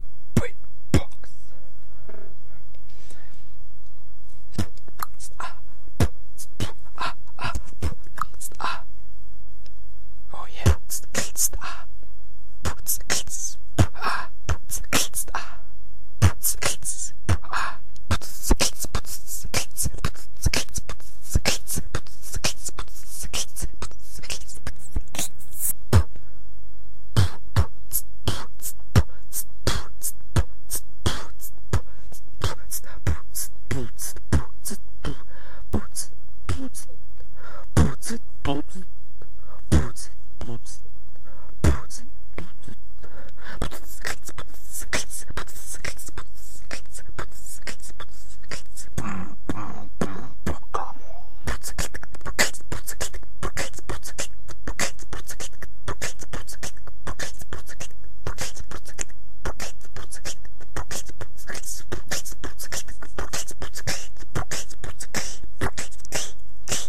Занимаюсь всего 18 дней, поэтому за простоту битов не судите сильно)
хайхэты точно надо подработать, они в один не должны сливаться
кики отработай, старайся делать его без звука, т.е. без всякого "пу" и т.п.)
beatbox.mp3